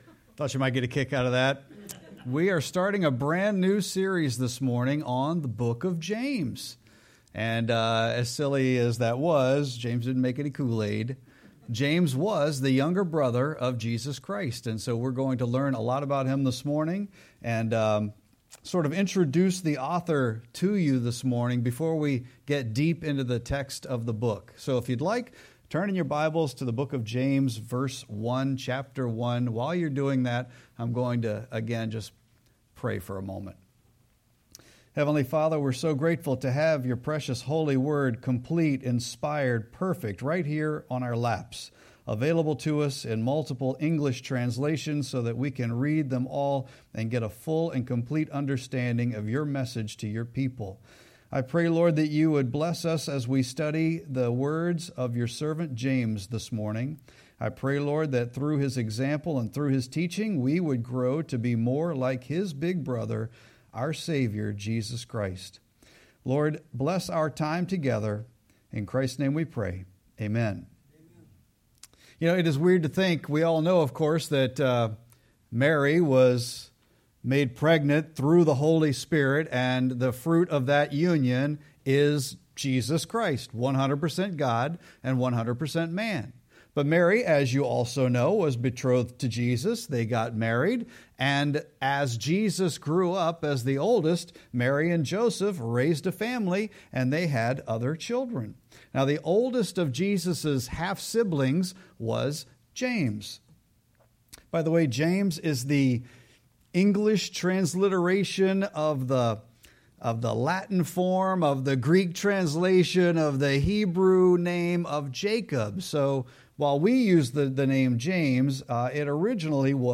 Sermon-4-27-25.mp3